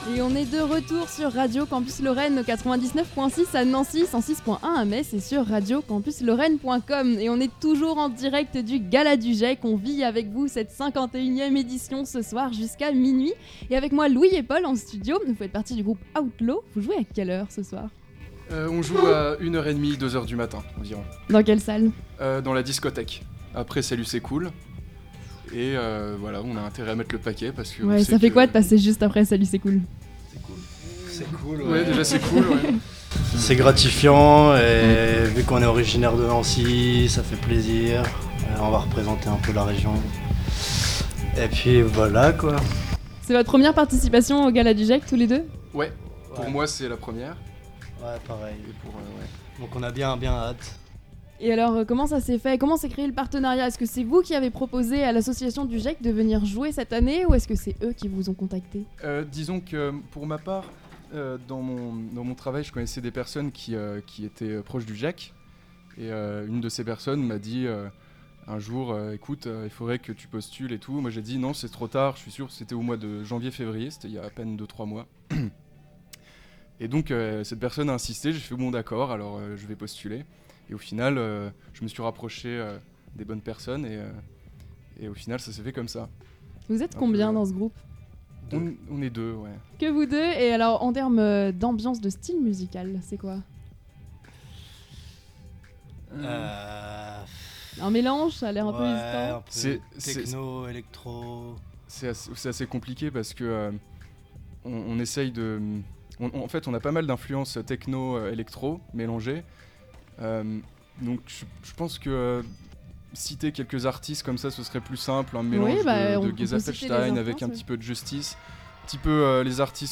Radio Campus Lorraine était en direct de 20h à minuit, l’occasion de vivre en live le début de la soirée.
gec_itw_outlaw.mp3